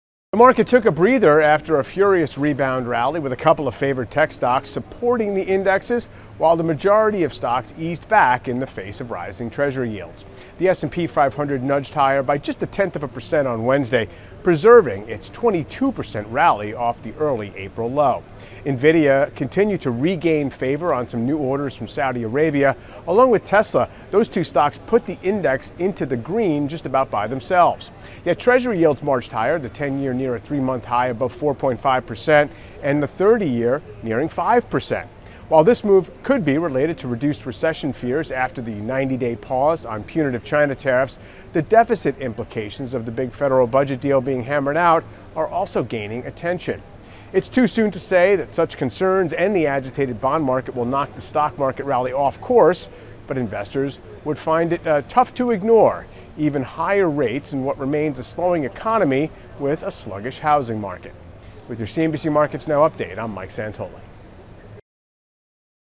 CNBC Markets Now provides a look at the day's market moves with commentary and analysis from Michael Santoli, CNBC Senior Markets Commentator.